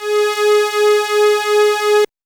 Index of /90_sSampleCDs/Vince_Clarke_Lucky_Bastard/SYNTHS/MULTIS